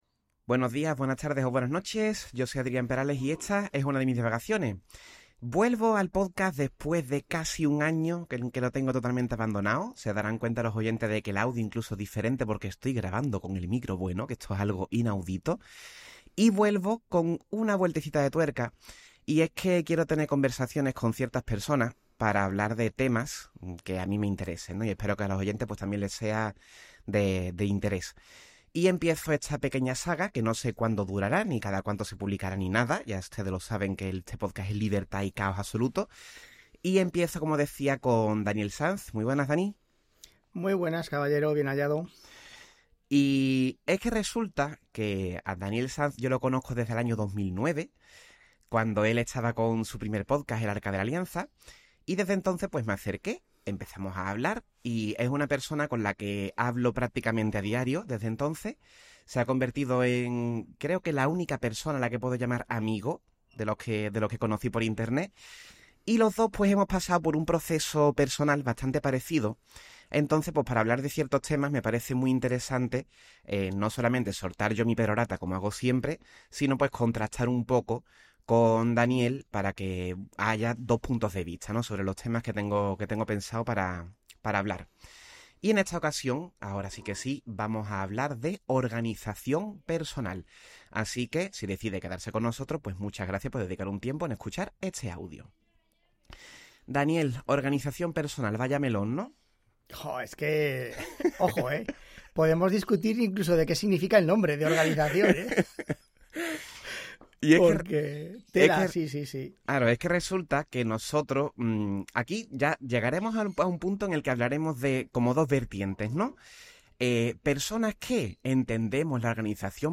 Conversación